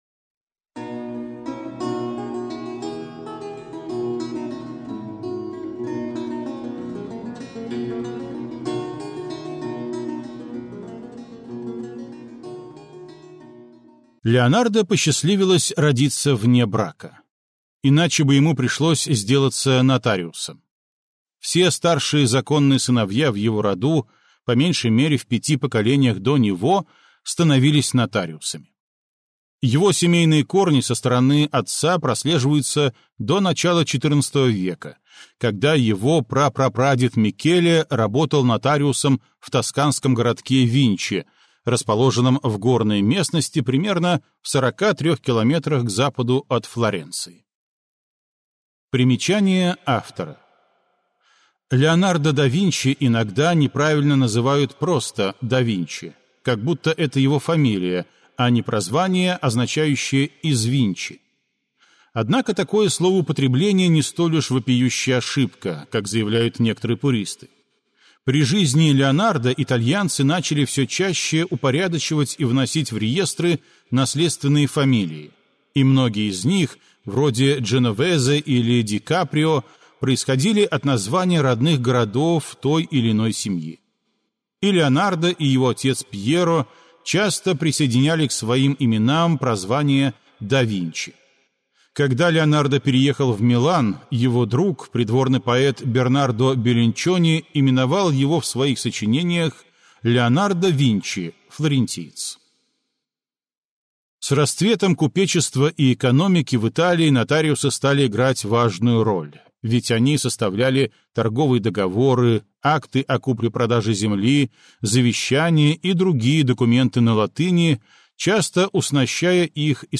Аудиокнига Леонардо да Винчи - купить, скачать и слушать онлайн | КнигоПоиск